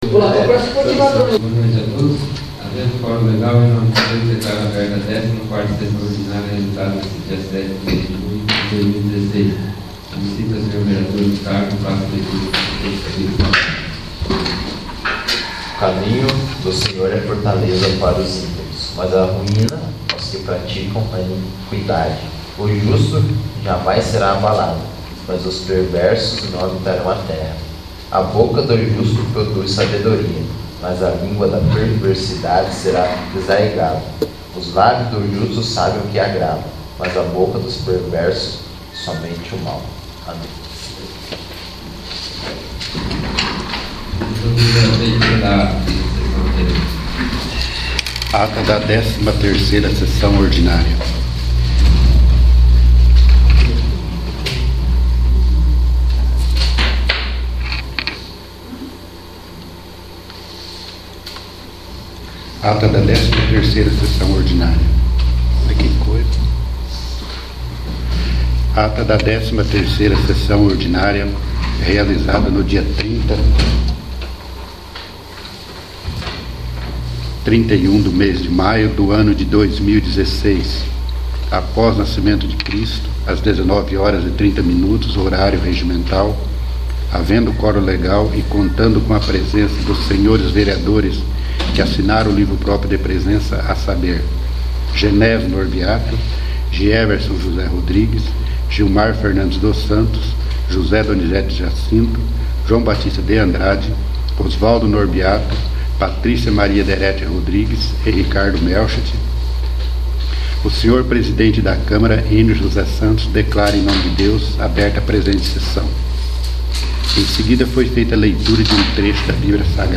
14º. Sessão Ordinária